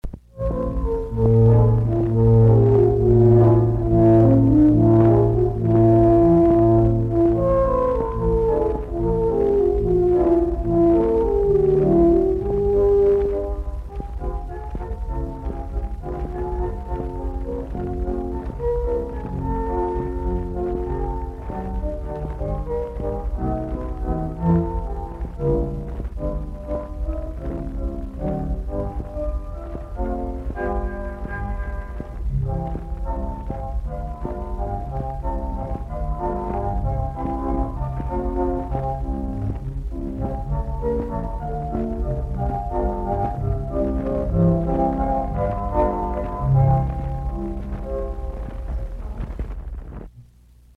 THE CHORALCELO
This abbreviated melody line of "Poor Little Butterfly" is extracted from a badly scratched original 78rpm glass master live 1942 recording, hand played
recorded in the Choralcelo Studio in New York City.
choralcelo_audio.mp3